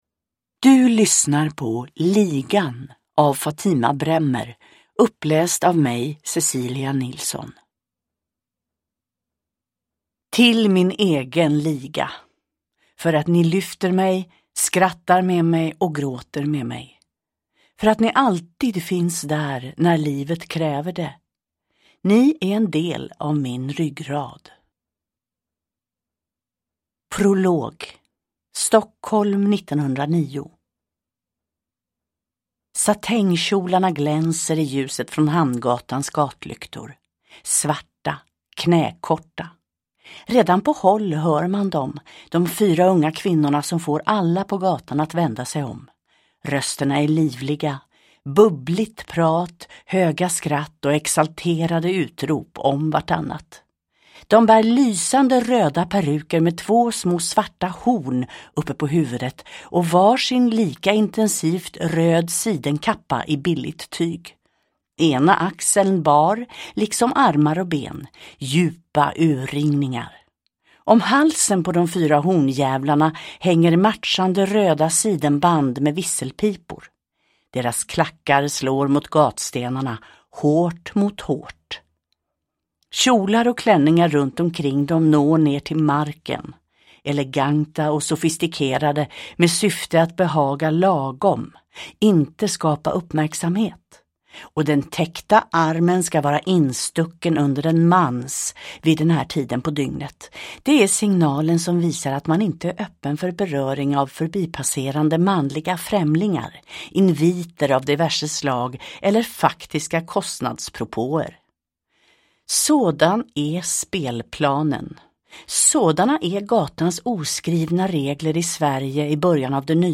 Ligan : Klarakvarterens blodsystrar eller ”En märklig explosion av kvinnlig intelligens” – Ljudbok